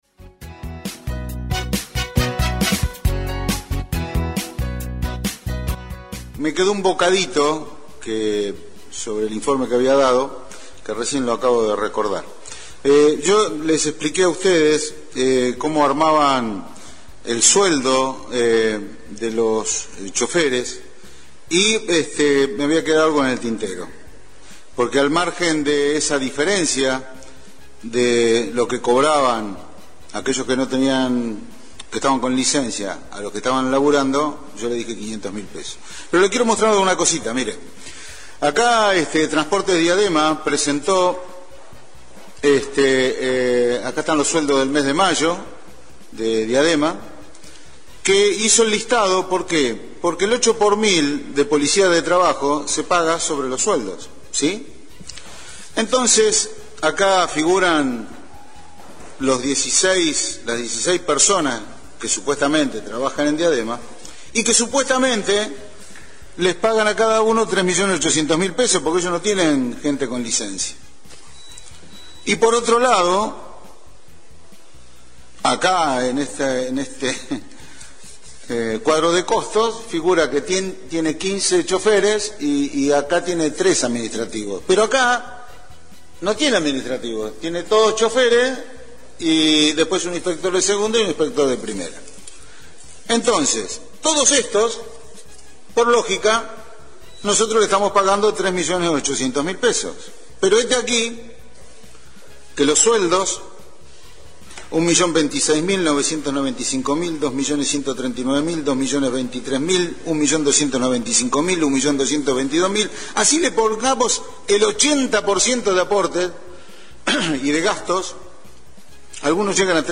En la primera sesión del Concejo Deliberante de este 2025, uno de los puntos salientes en la hora de preferencia fue la exposición del concejal Omar Lattanzio.